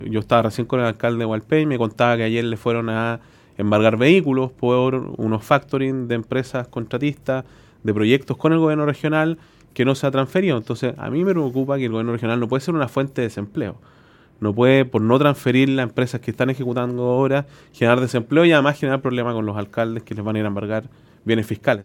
Hualpén es uno de los municipios afectados por esta situación, porque hay vehículos que fueron comprados con fondos del GORE y que hoy están con orden de embargo, afirmó Giacaman en conversación con Radio Bío Bío.